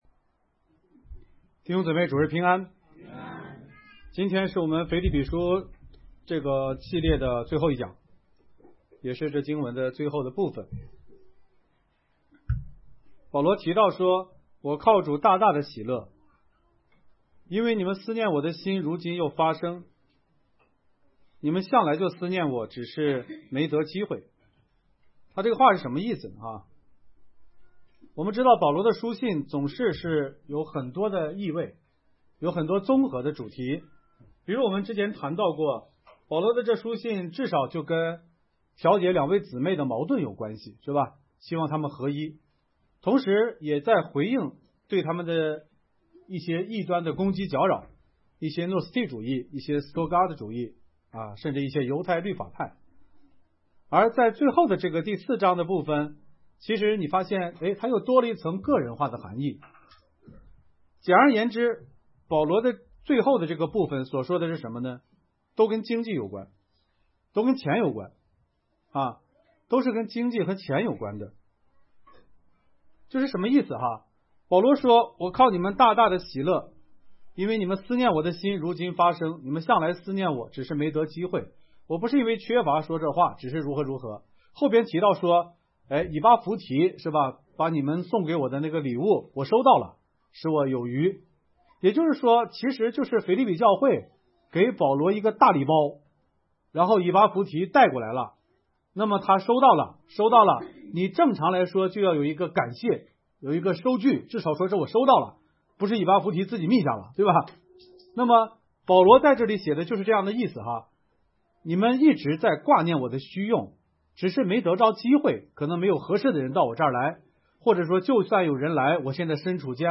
讲章